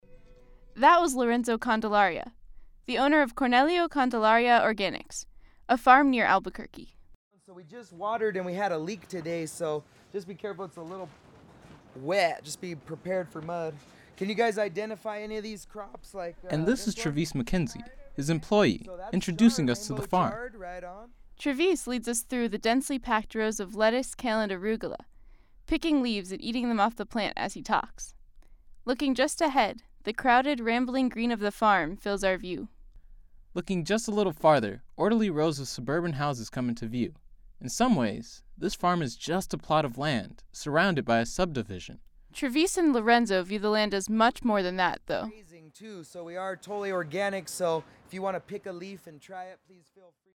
Audio Documentary/Public Affairs
Best-Documentary.mp3